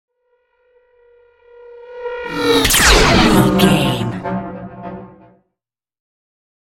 Sci fi shot whoosh to hit 721
Sound Effects
Atonal
No
futuristic
intense
woosh to hit